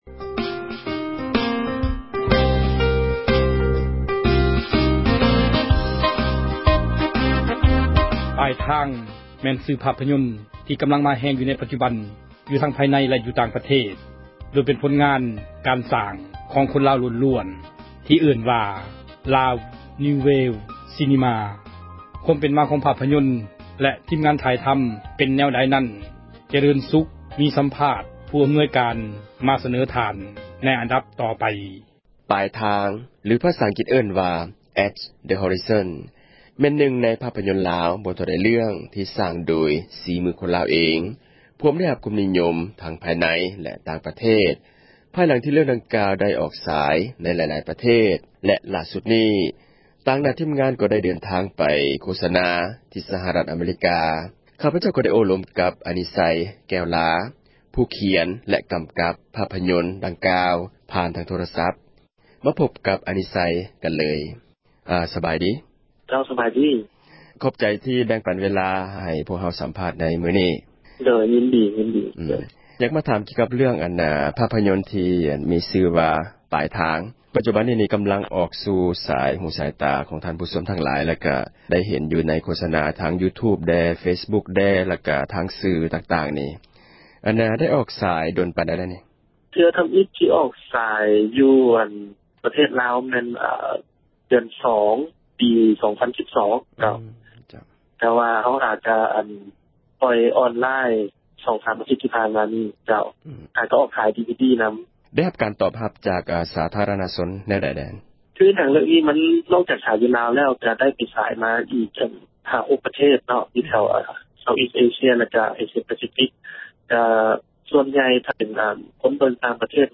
ມີສຳພາດ